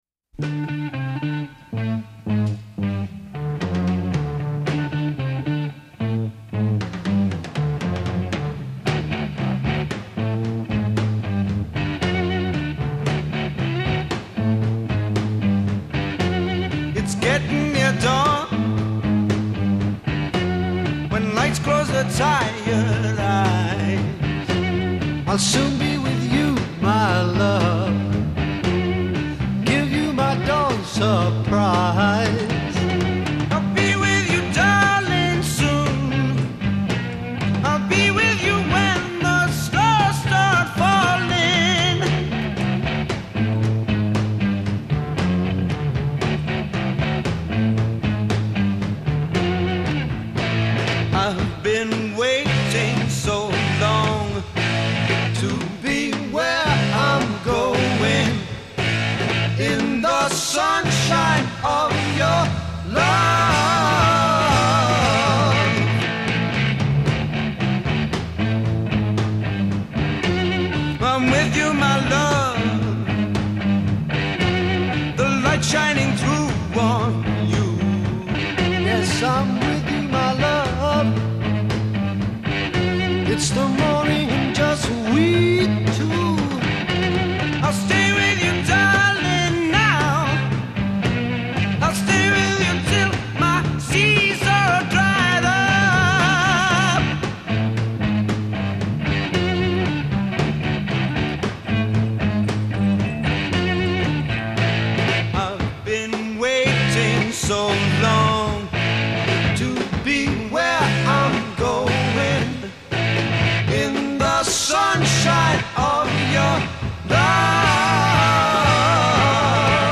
Introduction   Guitars, bass, and drums
A Verse   Solo voice over backing track. a
Refrain   First one voice, then two voices in harmony. b
B Verse   Guitar solo
Psychedelic Blues